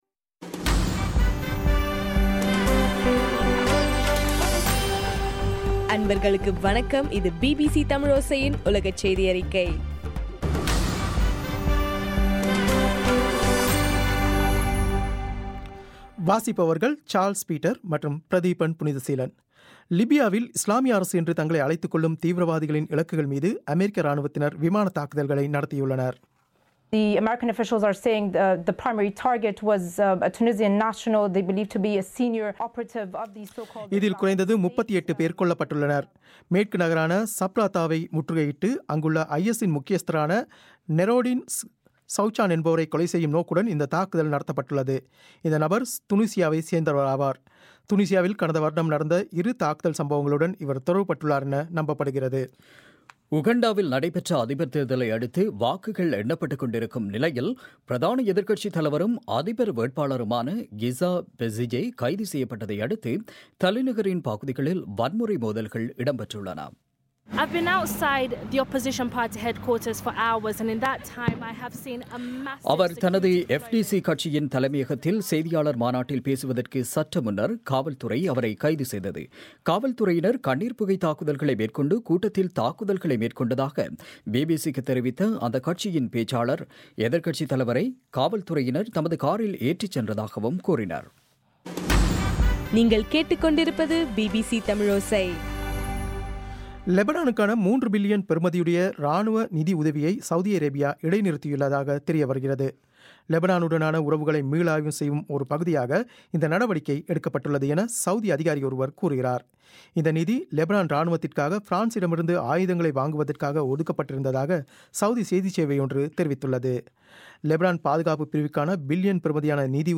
பிபிசி தமிழோசை உலகச் செய்தியறிக்கை- பிப்ரவரி 19